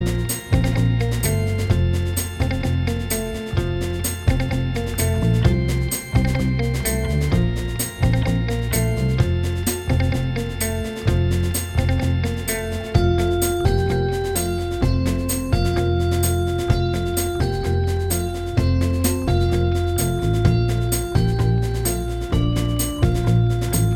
Minus Guitars Except Rhythm Pop (2010s) 3:16 Buy £1.50